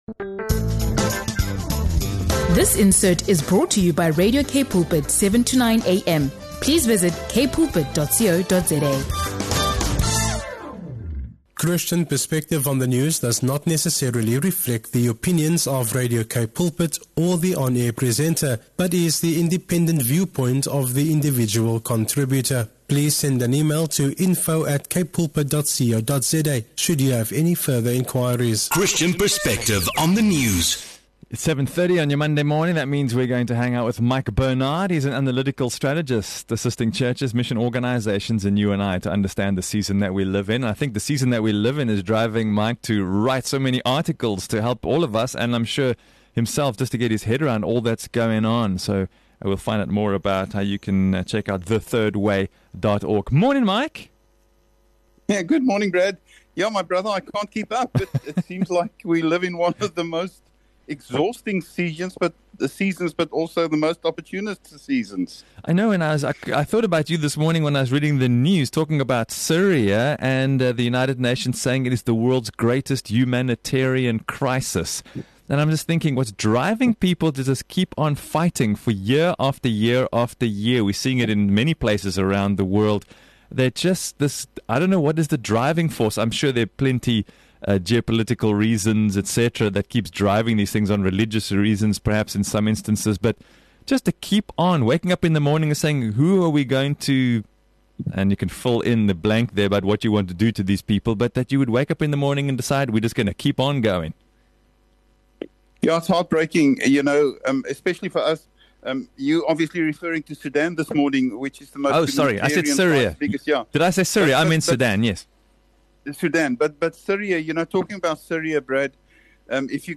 This thought-provoking conversation challenges believers to respond with truth, compassion, and courage in an age of violence, fear, and uncertainty.